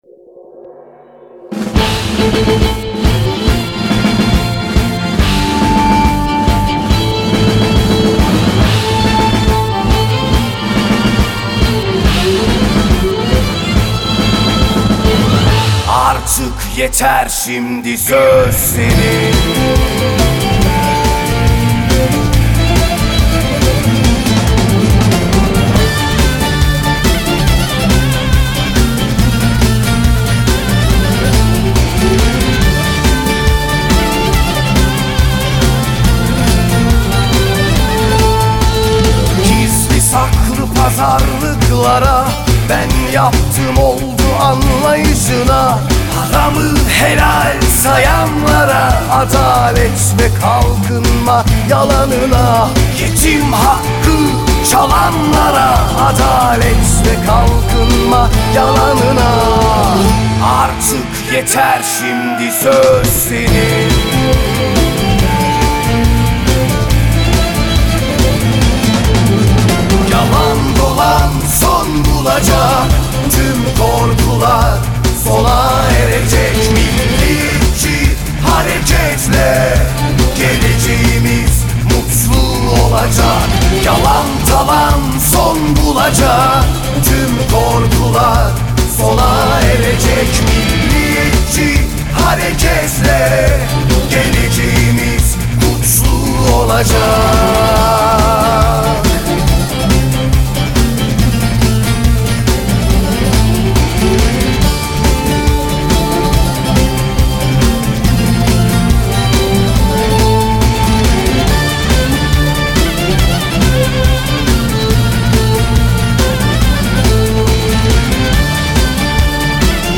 Kampanya Şarkısı